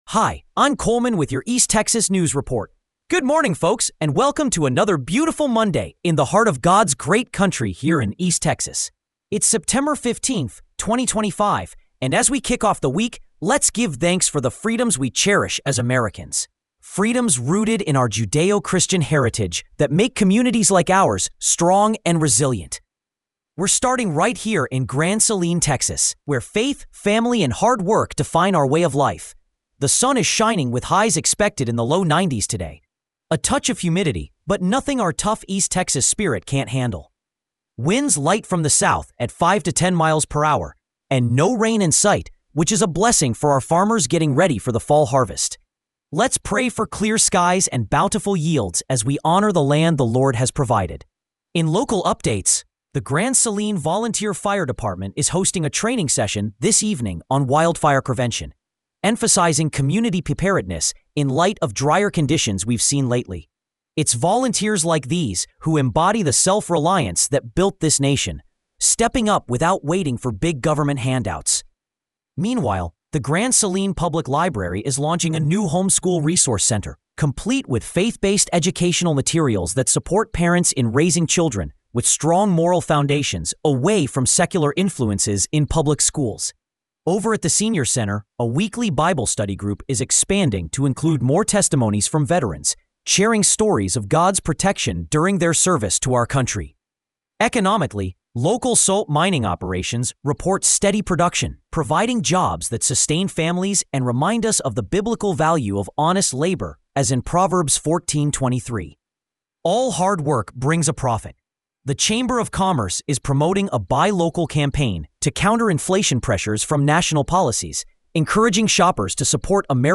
East Texas News Report for September 15, 2025